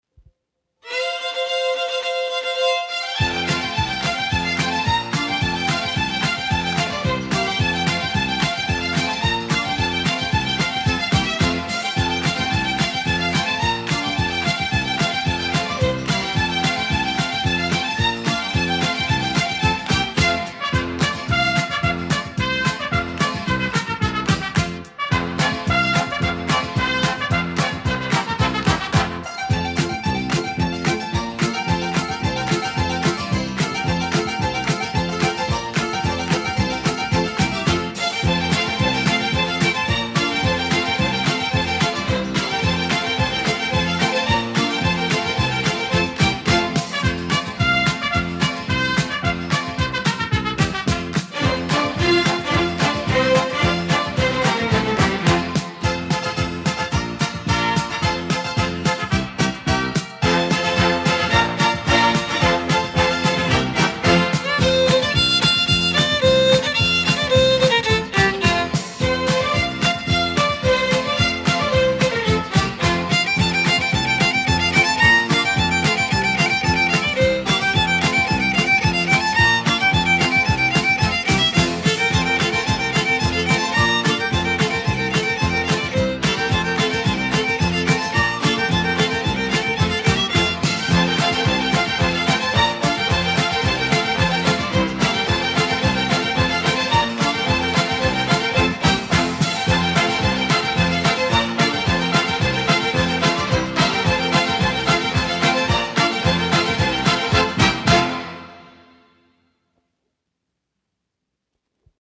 Genres:Easy Listening